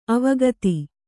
♪ avagati